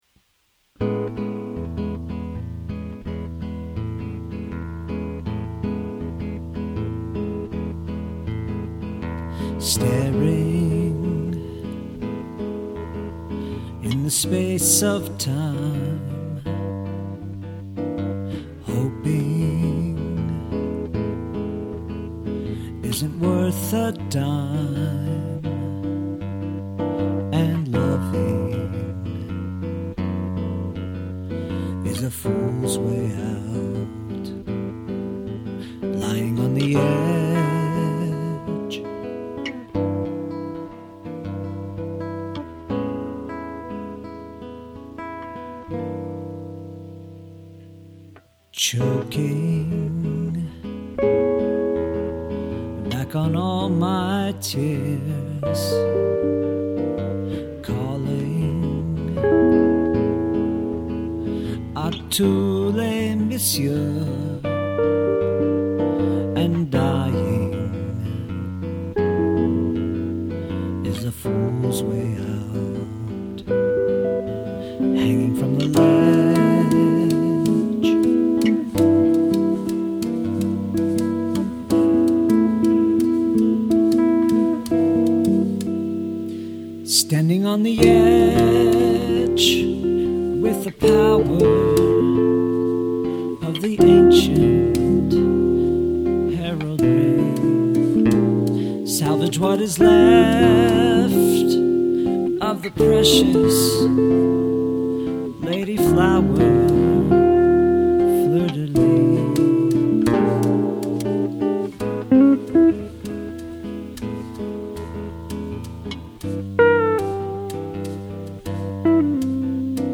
I like the jazz groove on the guitar and the brushed drums. All the instruments are really well played, and your singer is one of the best I know of.